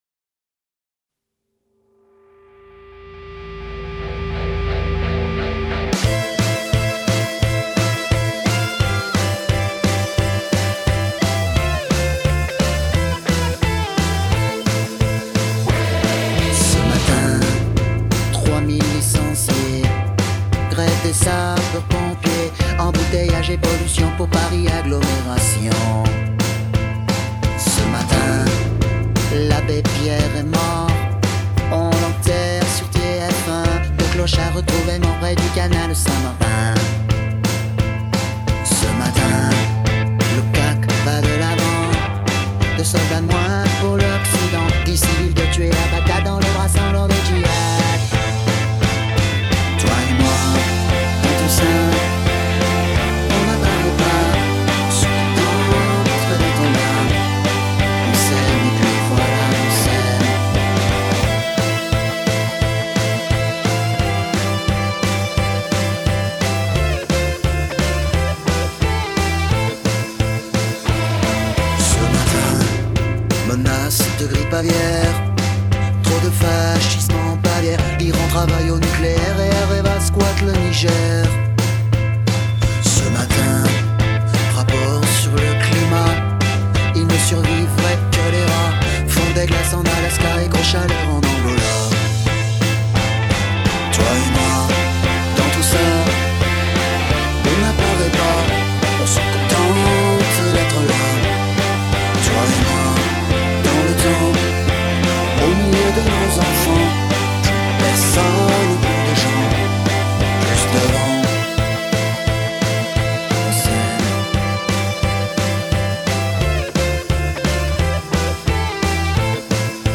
Acapella
Instrumentale